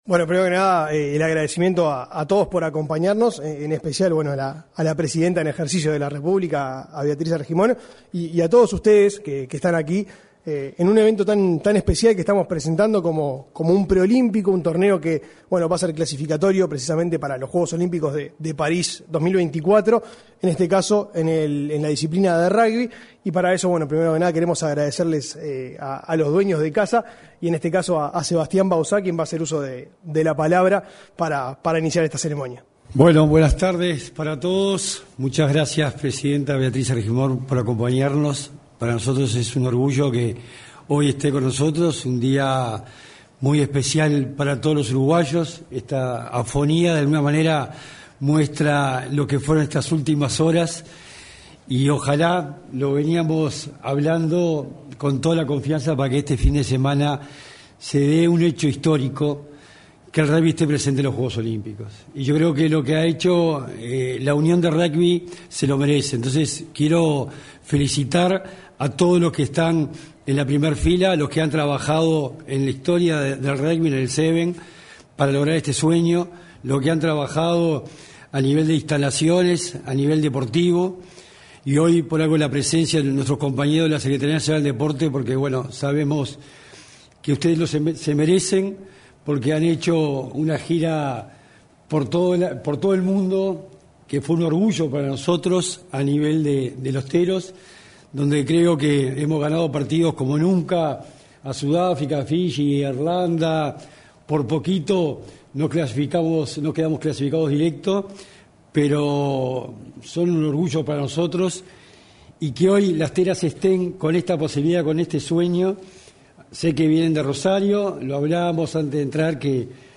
Este lunes 12, a las 17:30 horas, la Secretaría Nacional del Deporte realizo el lanzamiento del Torneo Preolímpico de Rugby Sevens, donde hablaron el secretario nacional del Deporte Sebastián Bauzá